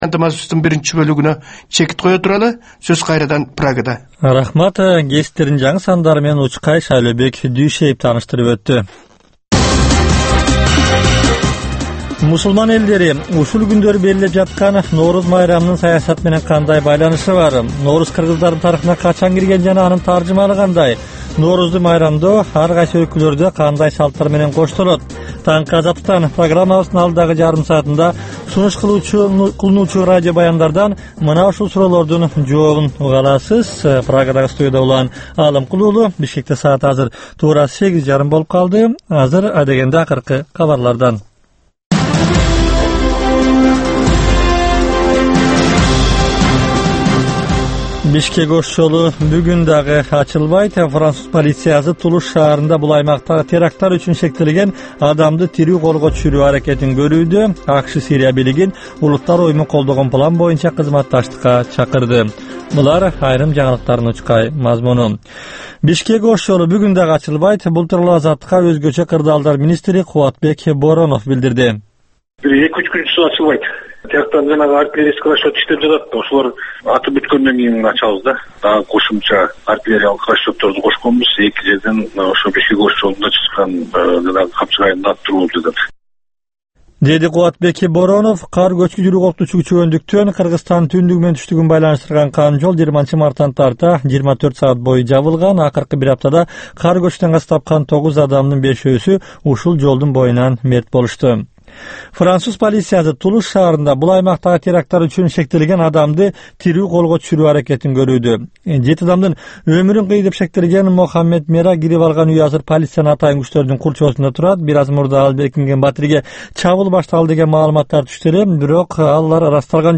Бул таңкы үналгы берүүнүн 30 мүнөттүк кайталоосу жергиликтүү жана эл аралык кабарлар, ар кыл орчун окуялар тууралуу репортаж, маек, күндөлүк басма сөзгө баяндама, «Коом жана турмуш» түрмөгүнүн алкагындагы тегерек үстөл баарлашуусу, талкуу, аналитикалык баян, сереп жана башка берүүлөрдөн турат. "Азаттык үналгысынын" бул берүүсү Бишкек убакыты боюнча саат 08:30дан 09:00га чейин обого чыгарылат.